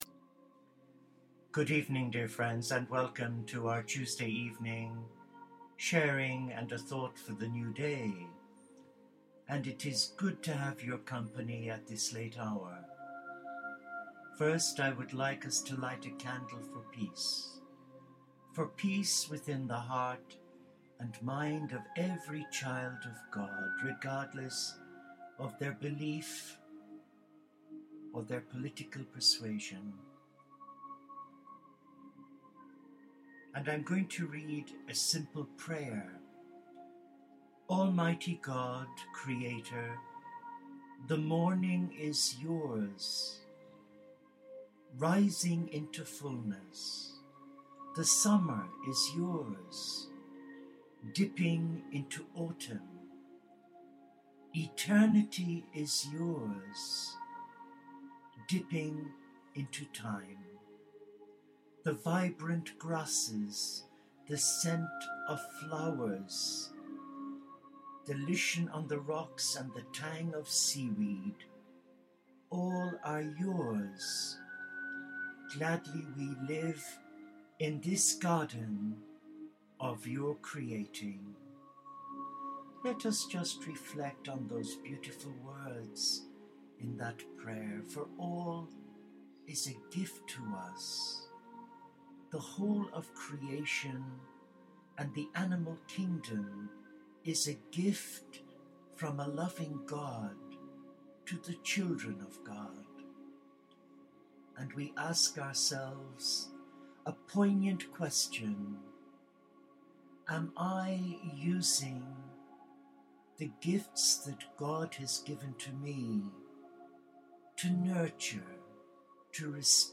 Our vigil concluded with a short guided meditation under the Lilac tree in our Monastery Garden.